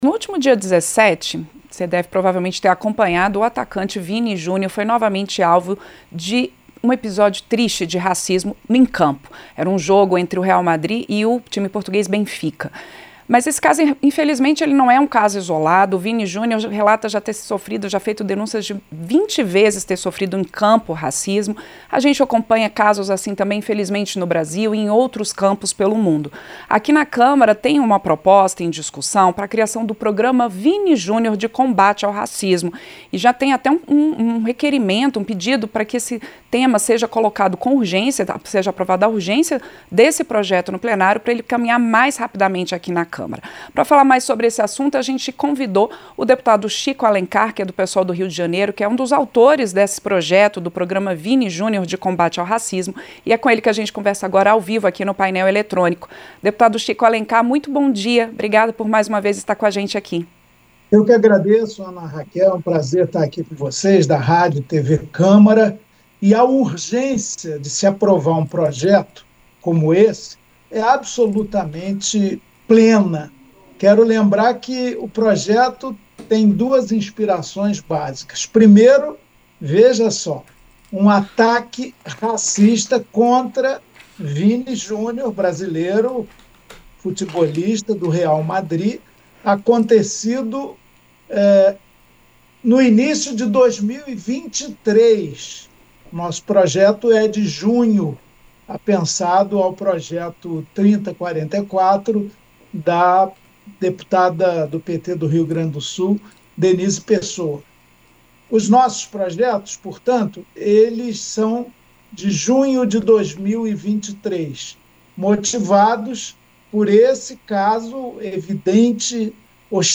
Entrevista: Dep. Chico Alencar (PSOL-RJ)